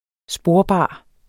Udtale [ ˈsboɐ̯ˌbɑˀ ]